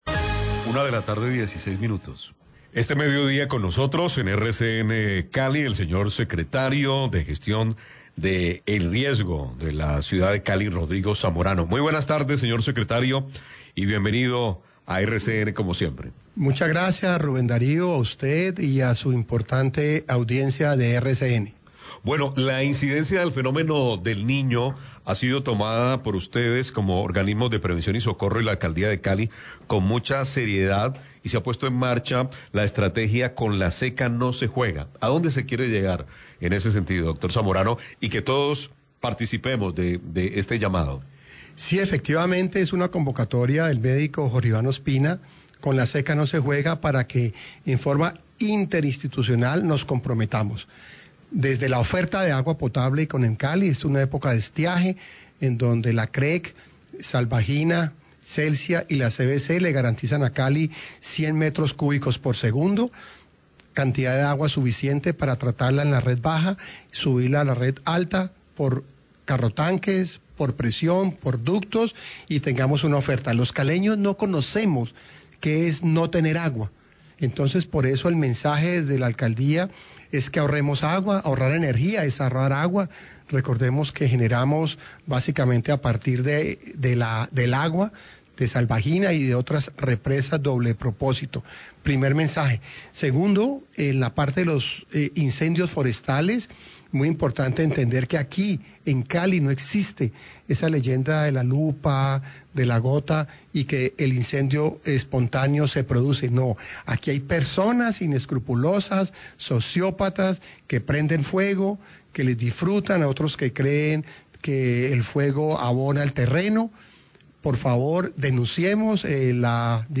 Secretario Gestión de Riesgo sobre estrategia 'Con la seca no se juega', RCN Radio 116pm
Radio